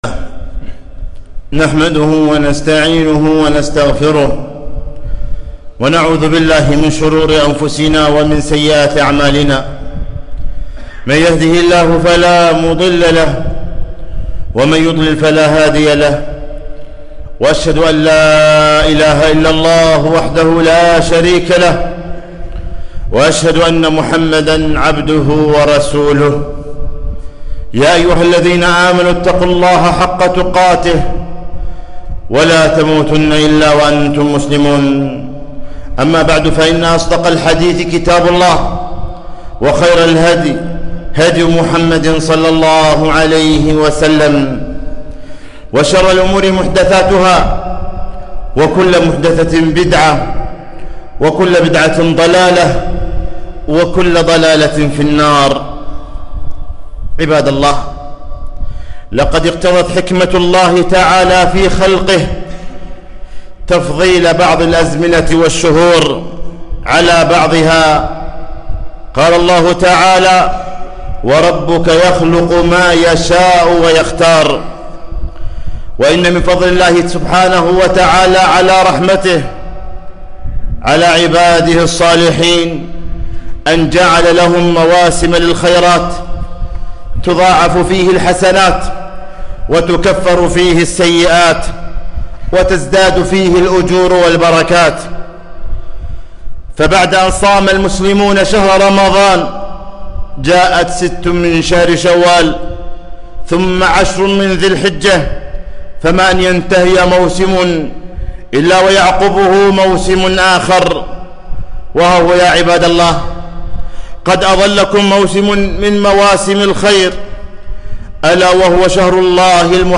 خطبة - شهر الله المحرم 4-1-1443